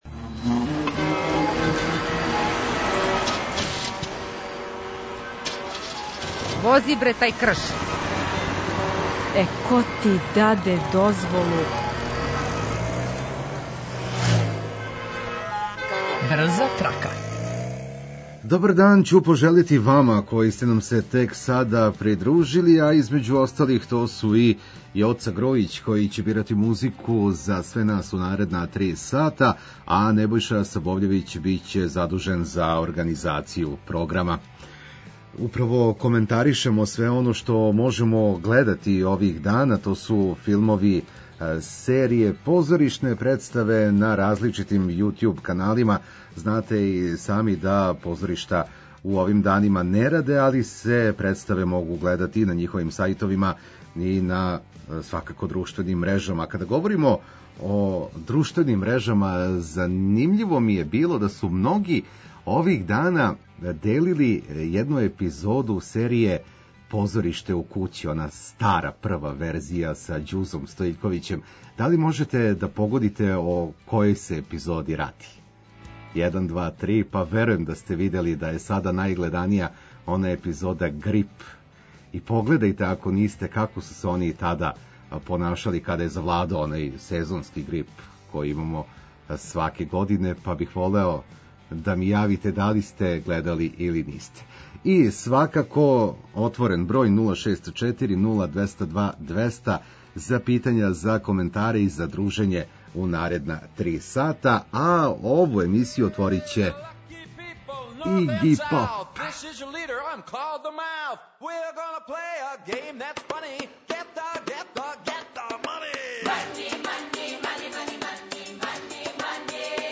Ту је и одлична музика која ће вам олакшати сваки минут, било где да се налазите.